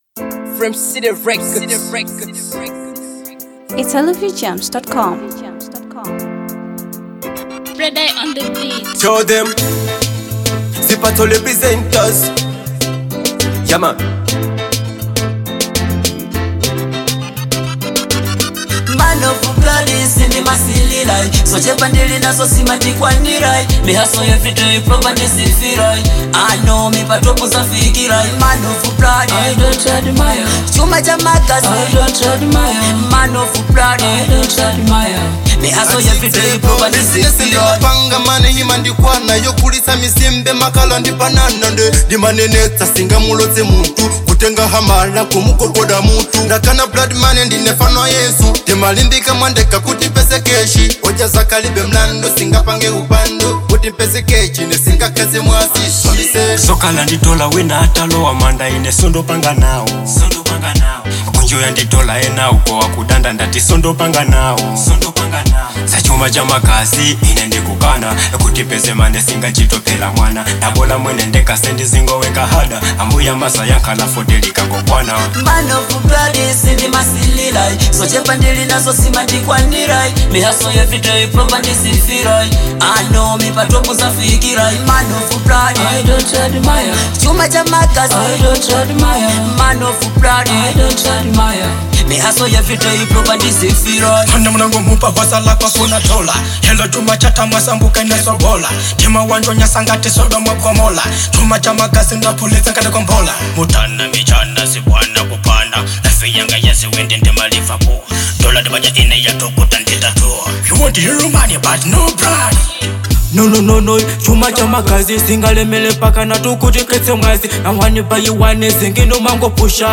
Afro Dancehall